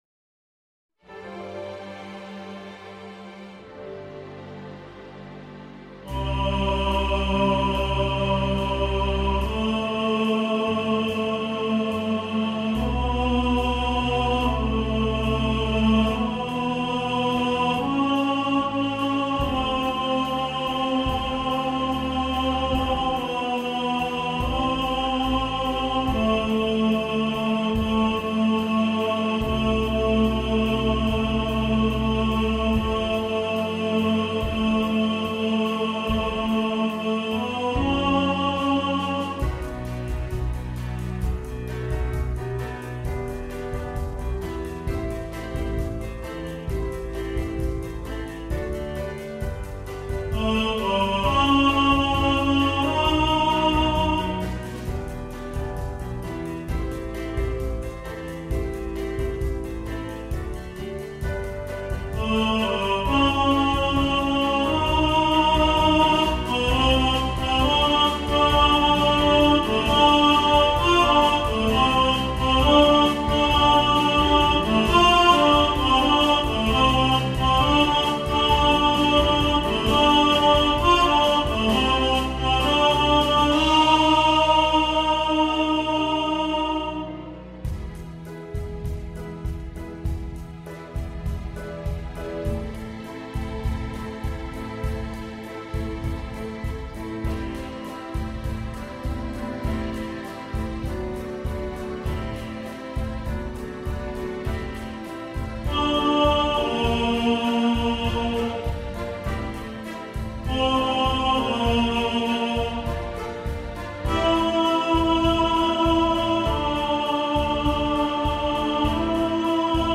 Bacharach Medley – Tenor | Ipswich Hospital Community Choir